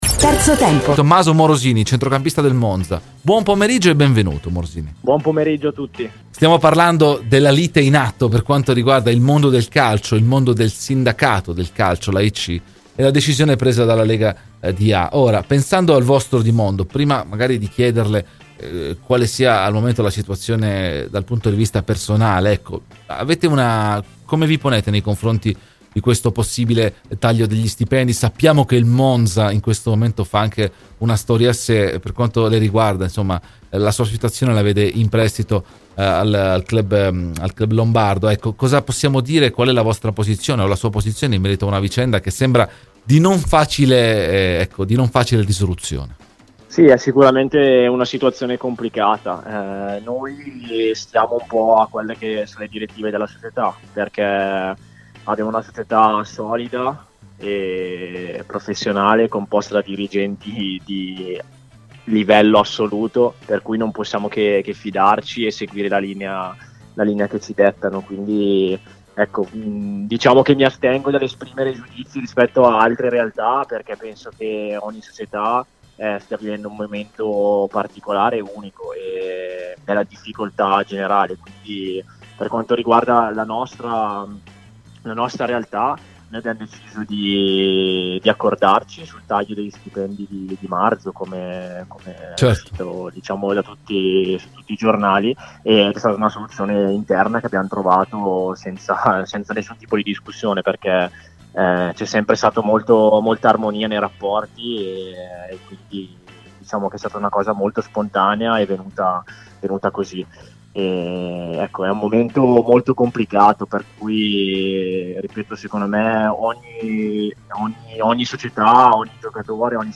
è intervenuto oggi ai microfoni di Radio Bianconera nel corso di ‘Terzo Tempo’